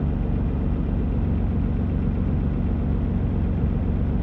rr3-assets/files/.depot/audio/Vehicles/ttv6_01/ttv6_01_idle.wav
ttv6_01_idle.wav